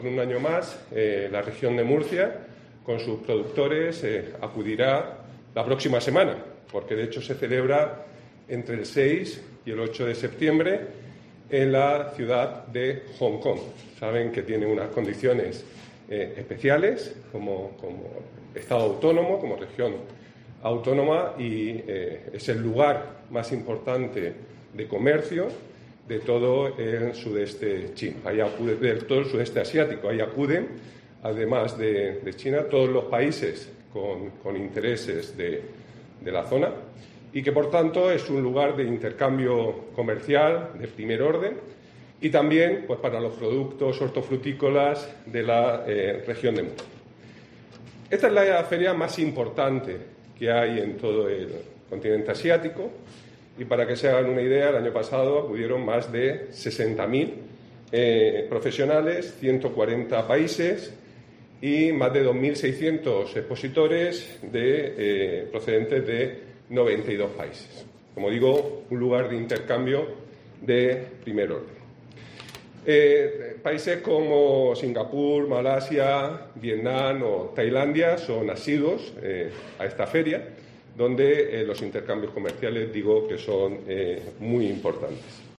Juan María Vázquez, consejero en funciones de Agua, Agricultura, Ganadería y Pesca